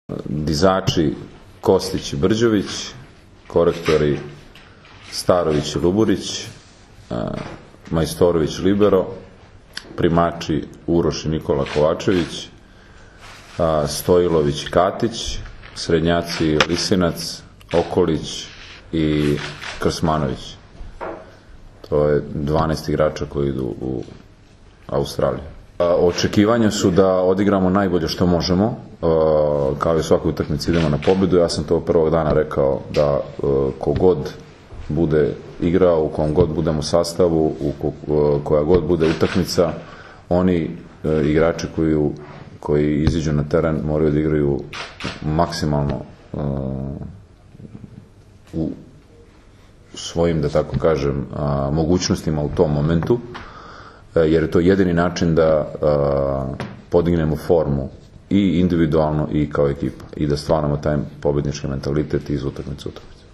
IZJAVA NIKOLE GRBIĆA 1